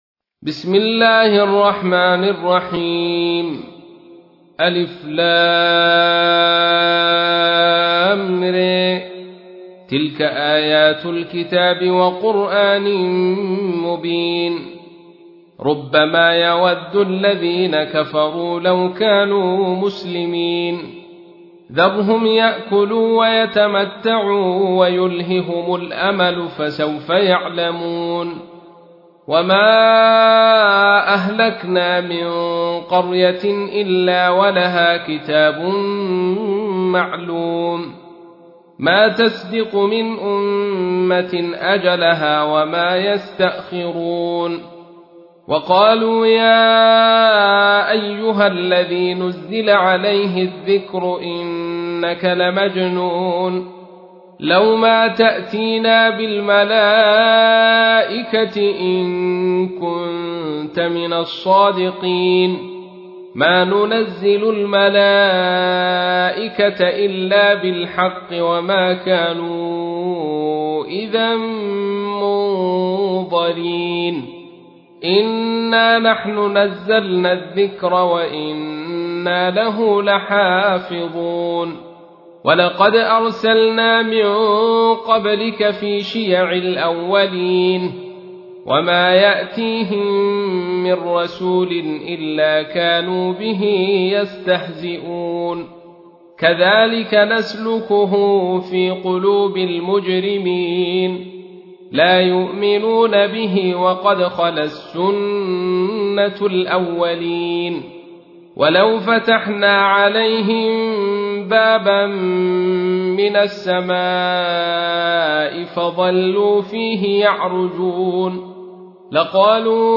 تحميل : 15. سورة الحجر / القارئ عبد الرشيد صوفي / القرآن الكريم / موقع يا حسين